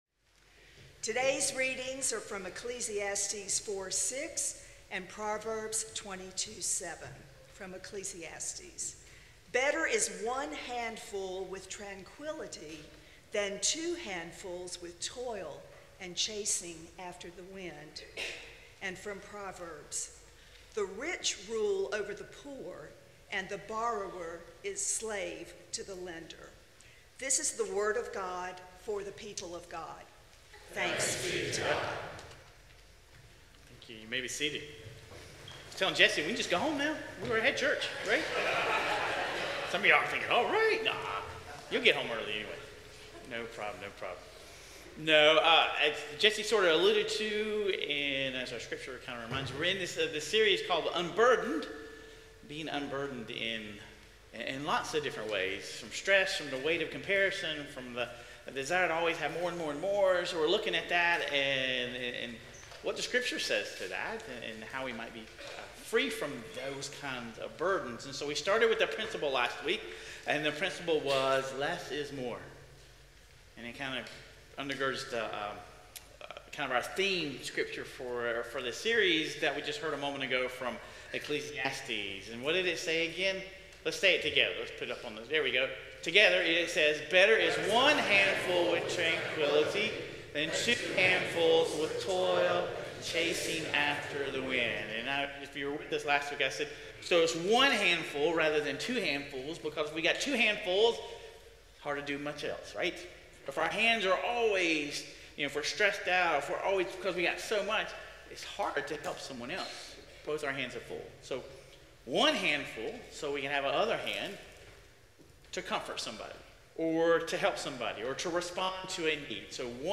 Sermon Reflections